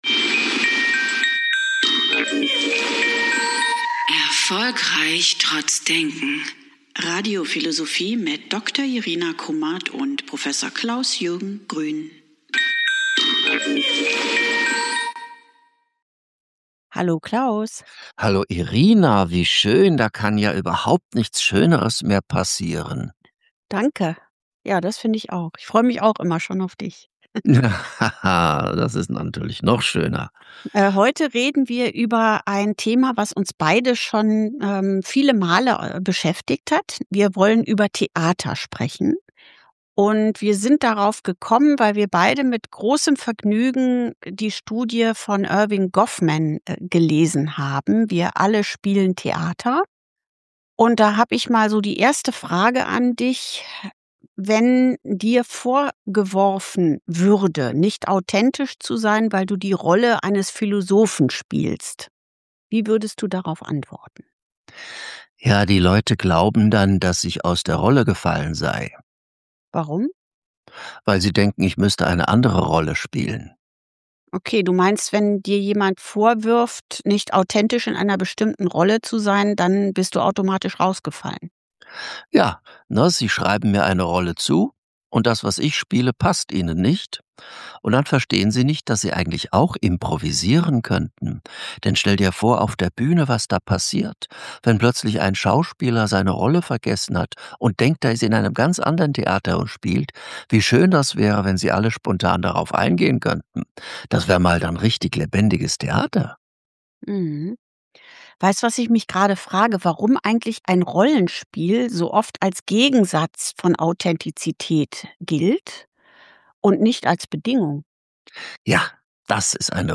Wenn das soziale Leben nurmehr eine Abfolge von Aufführungen ist– inwiefern unterscheidet sich das Theater noch vom Alltag, wenn wir ohnehin ständig „Rollen spielen“? Die beiden Philosophen
im Gespräch über Theater.